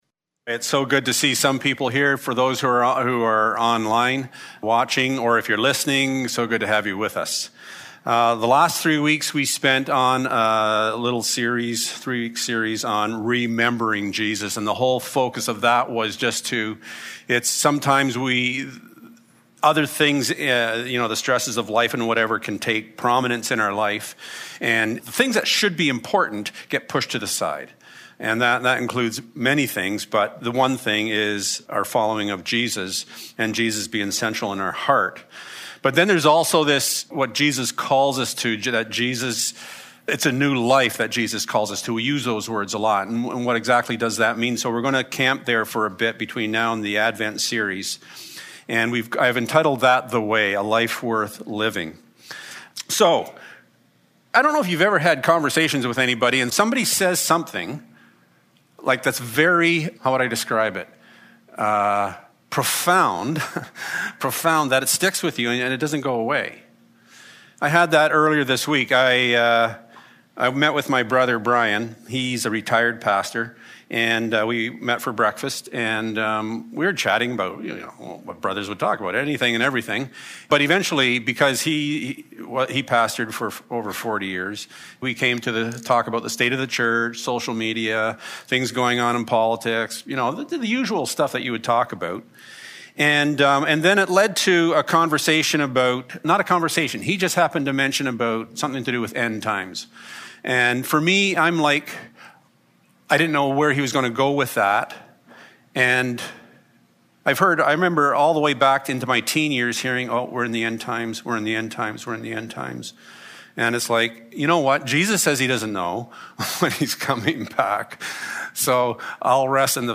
9-11 Service Type: Sunday Morning THE WAY One of the amazing things about love that emanates from God is the freedom of choice.